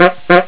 Sons urbanos 35 sons
buzina6.wav